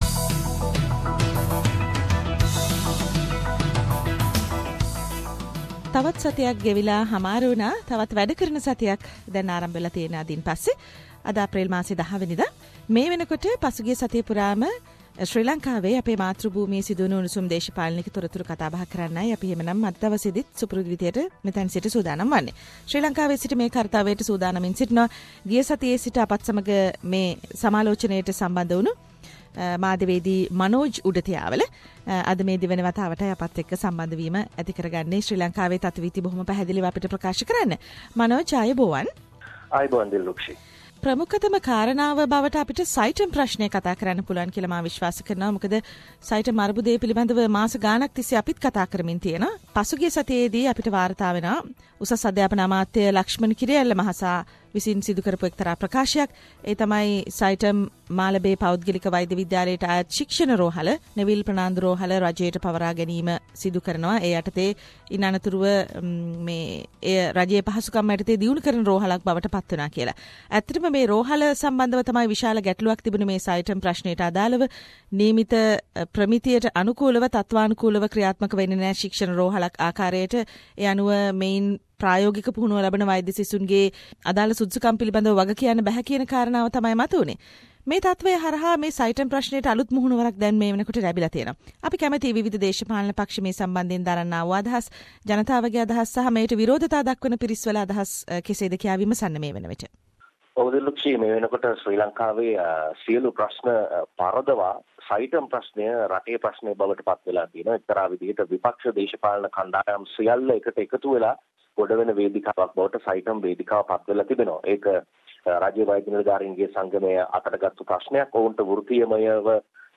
reports from Sri Lanka